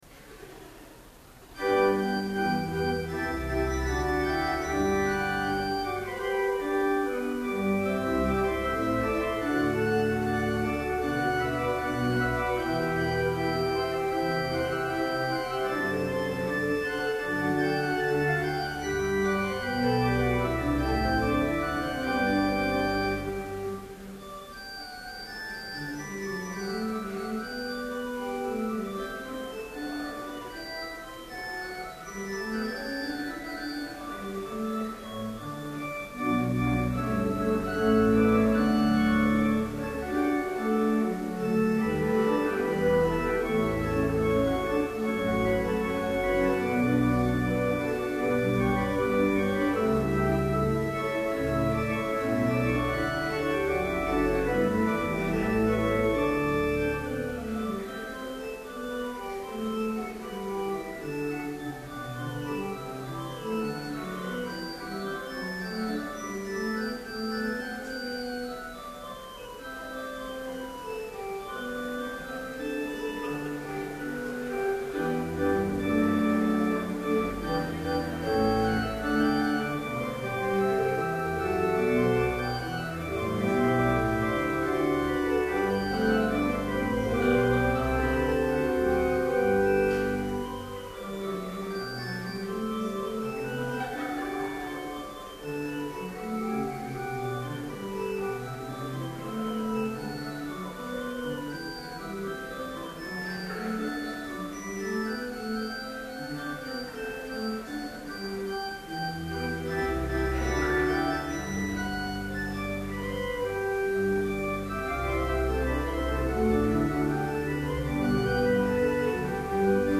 Complete service audio for Chapel - April 12, 2012